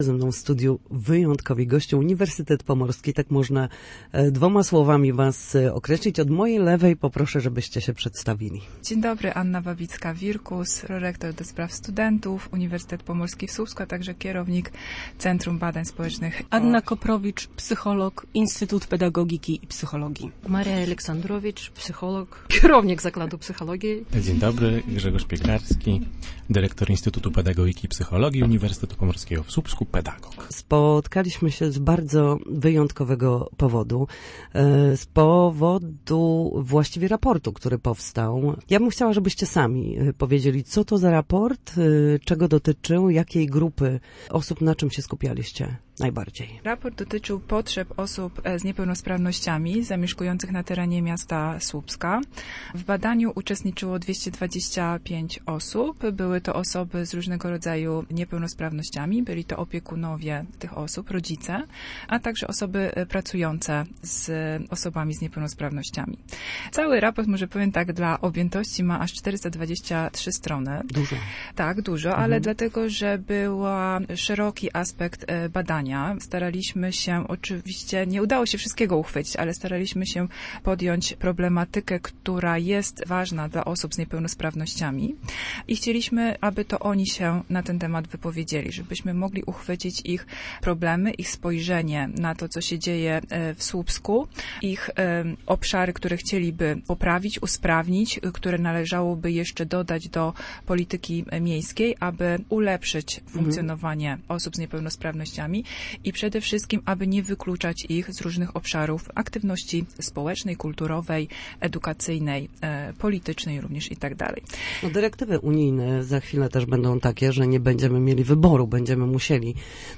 Na naszej antenie mówili o potrzebach, pracy nad raportem oraz wynikających z tego wnioskach.